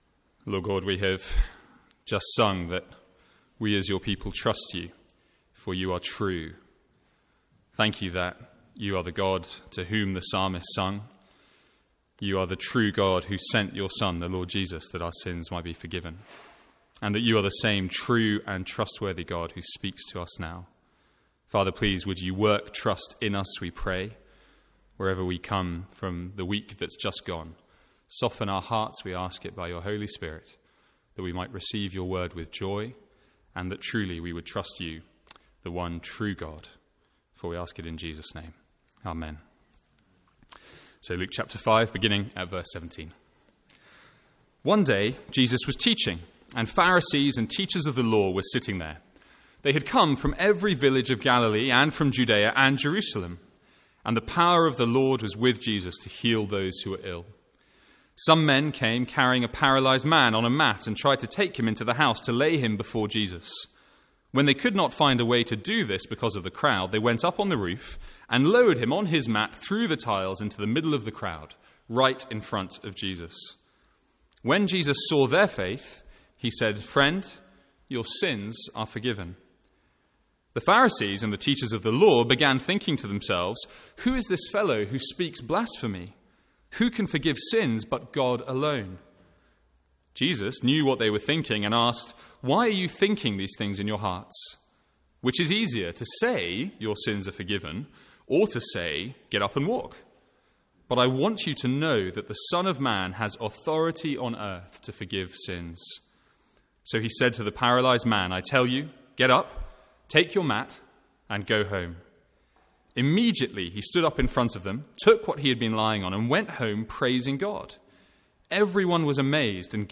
From the Sunday morning series in Luke.
Sermon Notes